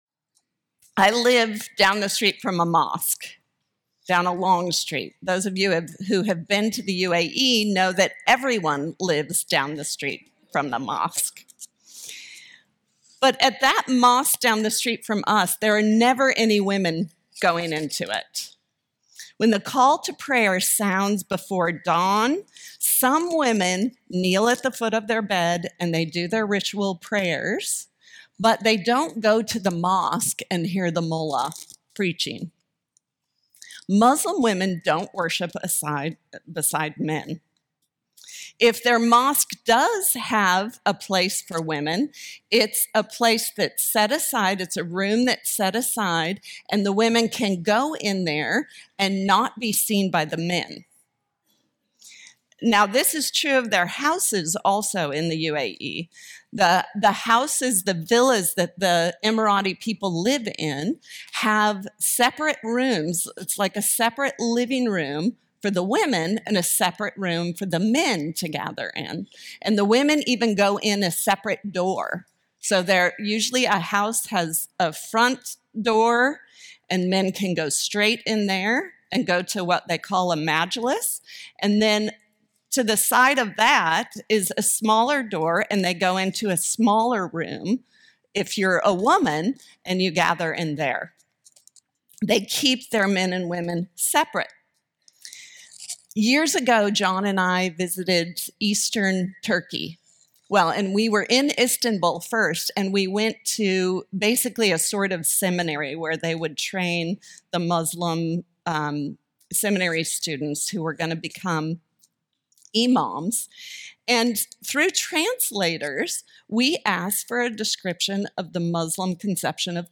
Audio recorded at MVBC’s 2025 Women’s Retreat.